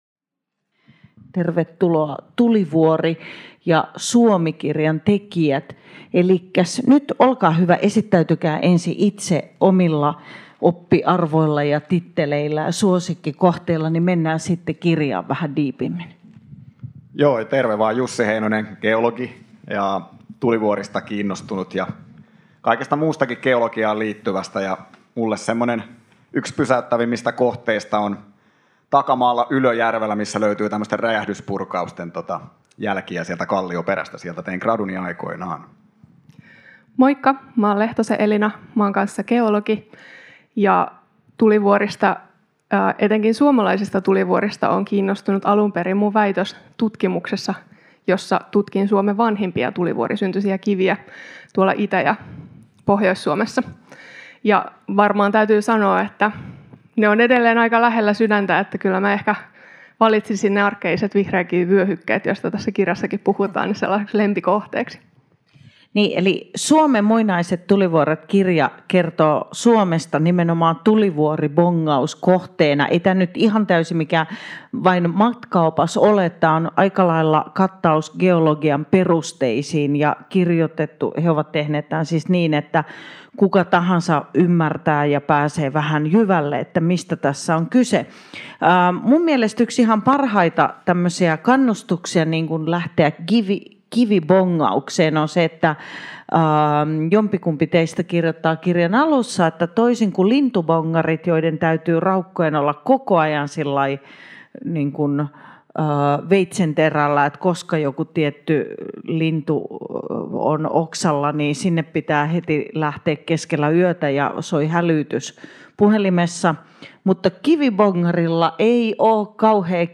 Ohjelma on taltioitu Tampereen Kirjafestareilla 2024.